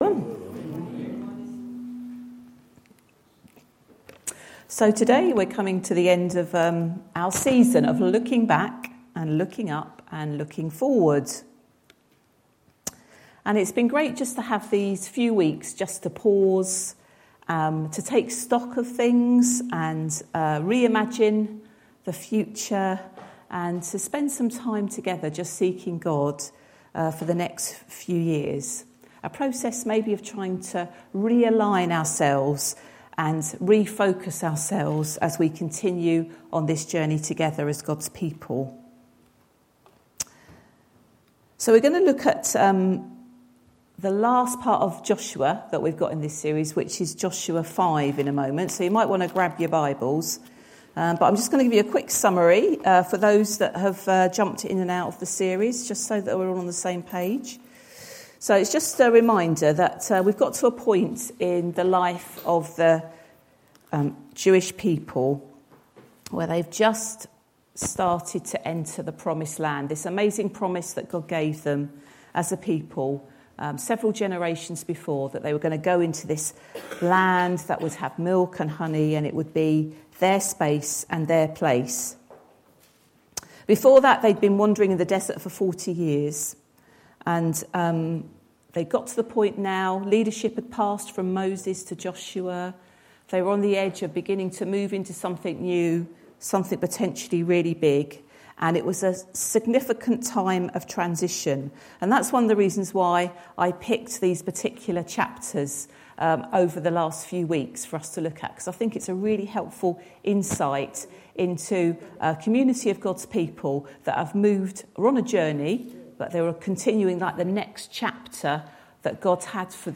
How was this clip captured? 20TH-JULY-SUNDAY-SERVICE.mp3